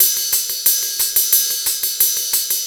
Ride 05.wav